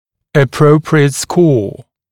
[ə’prəuprɪət skɔː][э’проуприэт ско:]соответствующий балл (при балльной оценке)